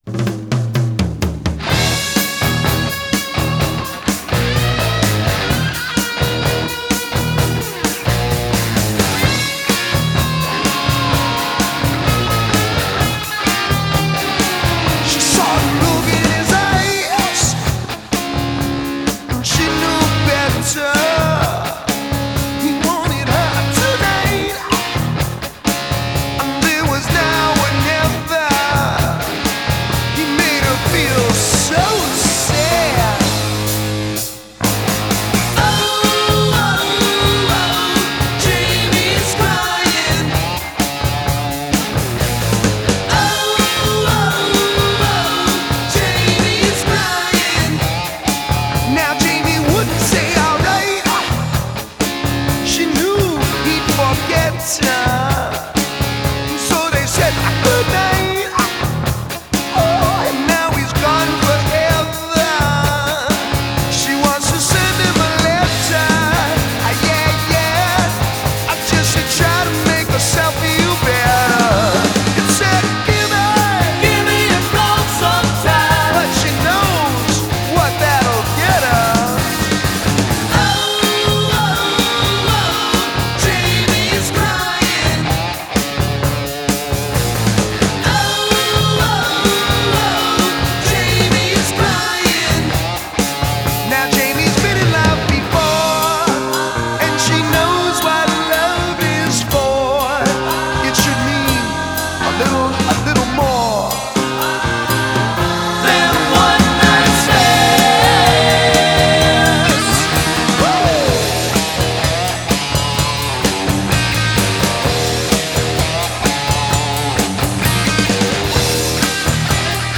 هارد راک Hard Rock Rock راک